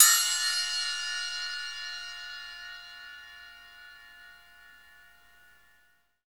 Index of /90_sSampleCDs/Roland L-CDX-01/CYM_FX Cymbals 1/CYM_Splash menu
CYM ROCK 09L.wav